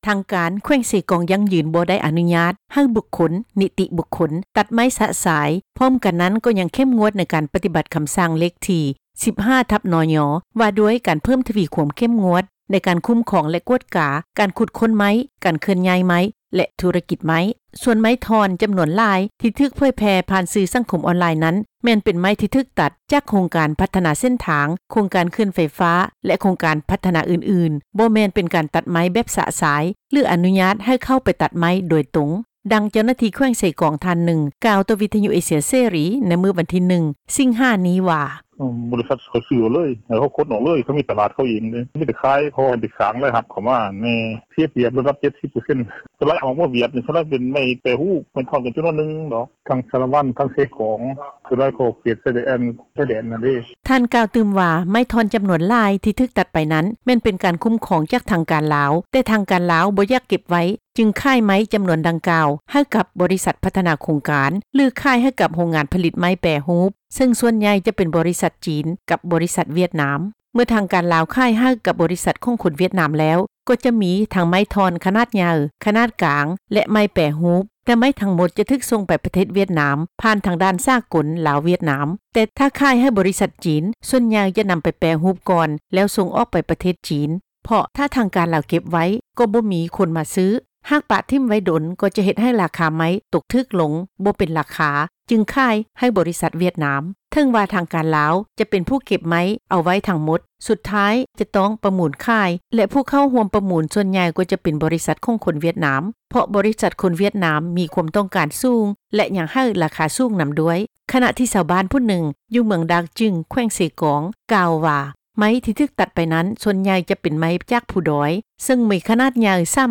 ແຂວງເຊກອງ ປະຕິເສດ ເຣື່ອງໃຫ້ບຸກຄົນ, ນິຕິບຸກຄົນ ຕັດໄມ້ຊະຊາຍ — ຂ່າວລາວ ວິທຍຸເອເຊັຽເສຣີ ພາສາລາວ